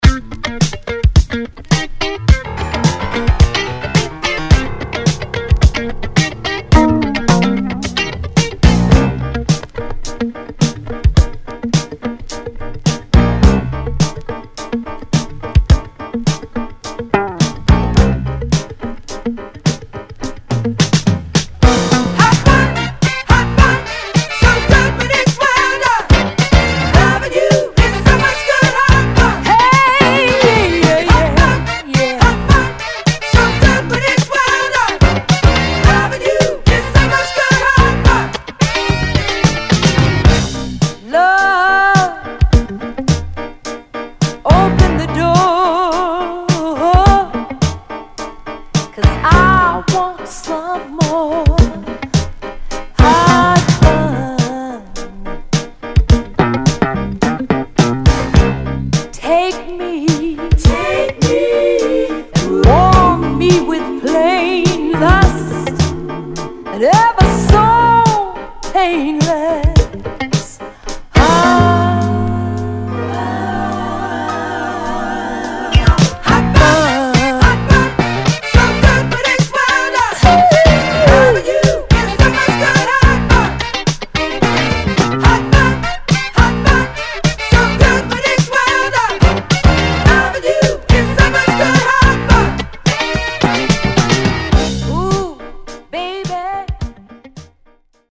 Funky Female Vocal Disco